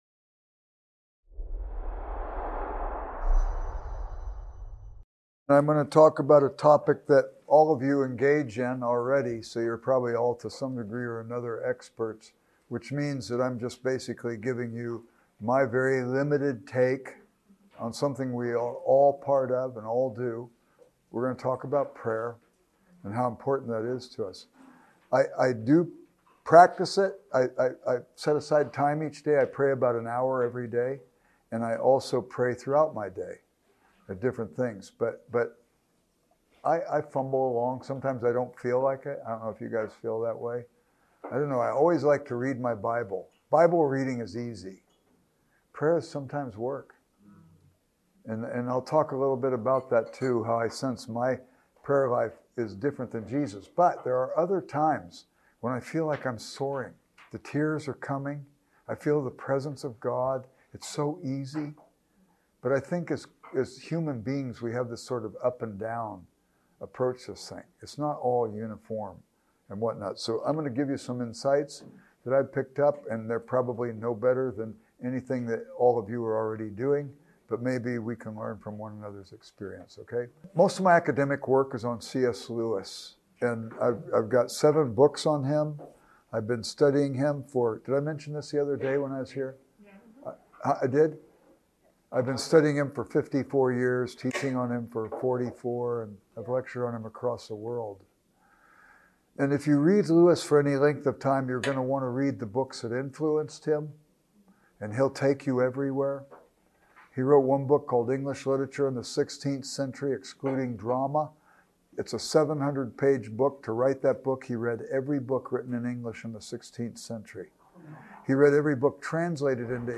Event: ELF Soul Care Network